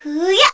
Kara-Vox_Attack4.wav